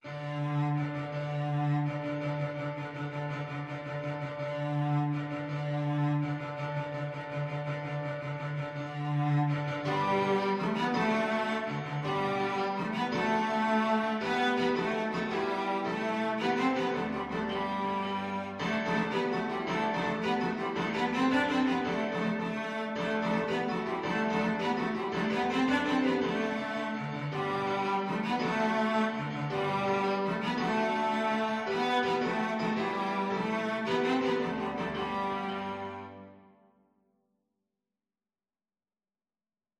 Cello
6/8 (View more 6/8 Music)
With energy .=c.110
G major (Sounding Pitch) (View more G major Music for Cello )
Classical (View more Classical Cello Music)